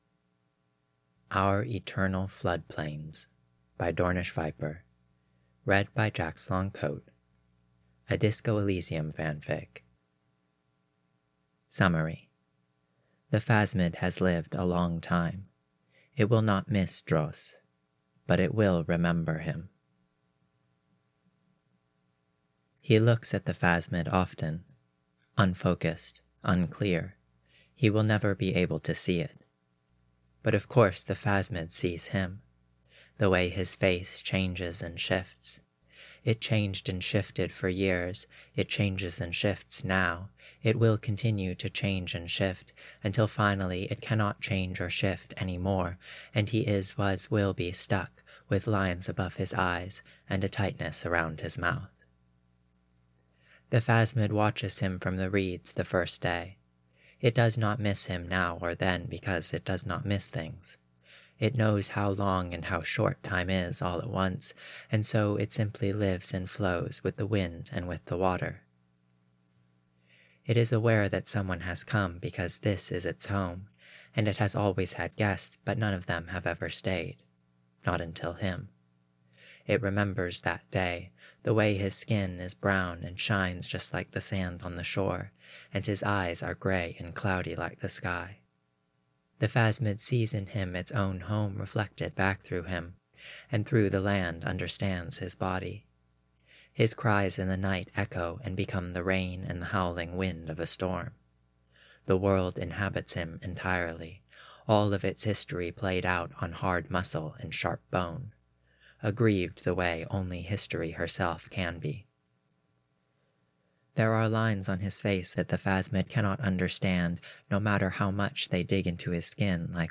collaboration|two voices